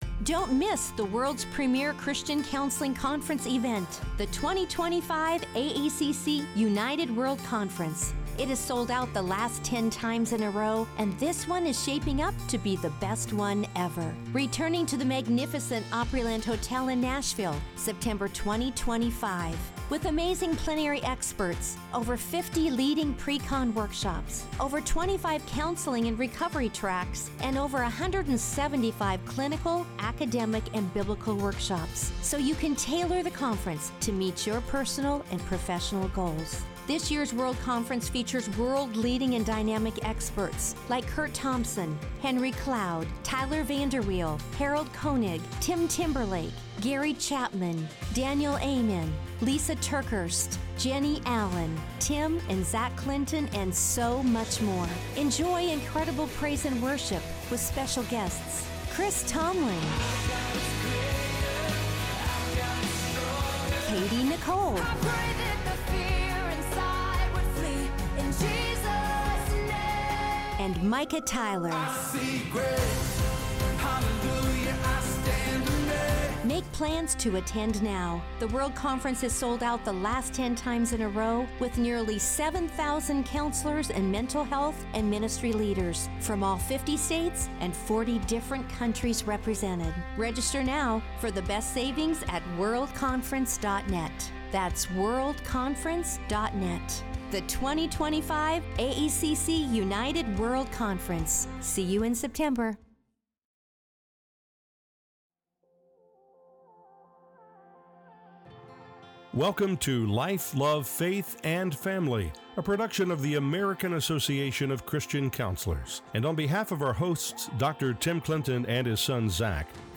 Listen as the two counselors reveal that anyone can suffer burnout- from professionals, to parents, care-givers, even students.